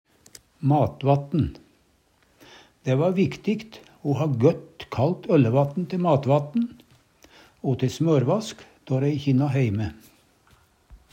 matvatten - Numedalsmål (en-US)